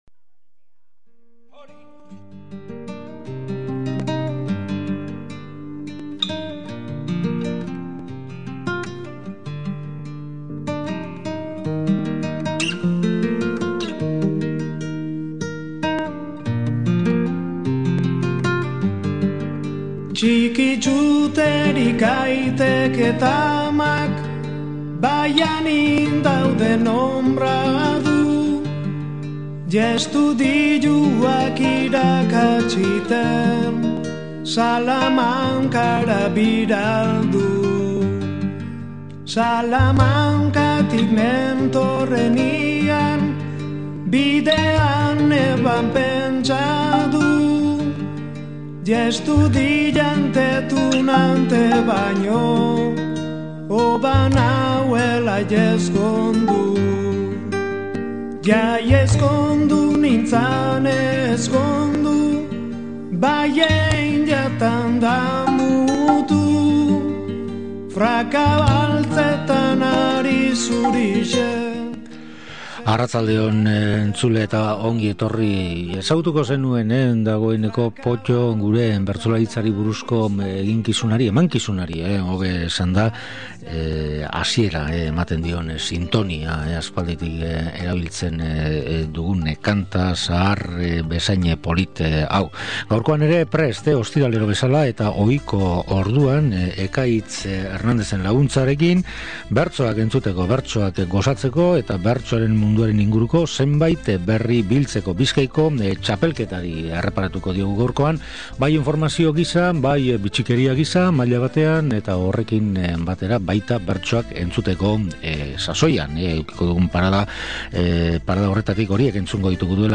2004an jokatu zen Bizkaiko Bertso Txapelketara jo du eta finalera sailkatzeko eskualdeka antolatu ziren saioetatik zenbait bertso emanaldi hautatu ditu. Zehazkiago esateko, Zeanuri (argazkian), Elorrio eta Bermeoko norgehiagokak gogoratu dizkigu bere hautalanean. Ildo beretik, azken hilabeteotan jokatzen ari den 2006ko Bizkaiko Txapelketak eskualdeka orain arte eman dituen emaitzei ere erreparatzen zaie.